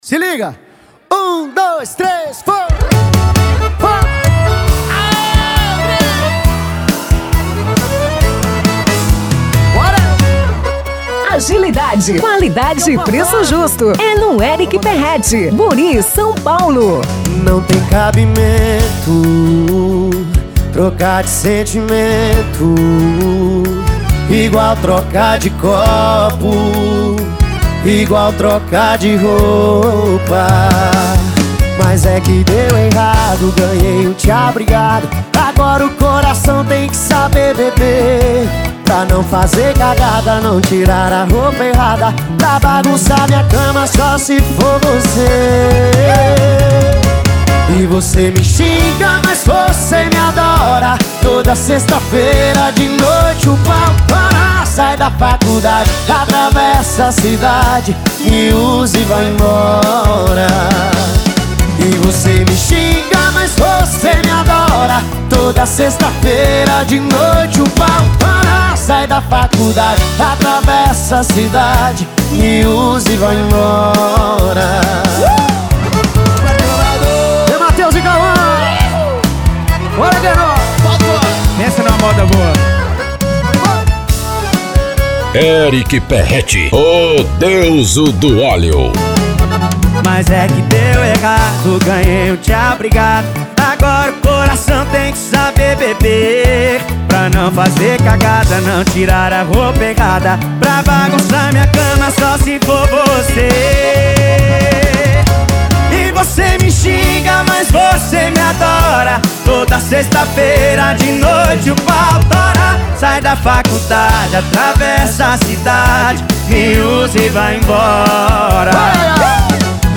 Sertanejo Universitário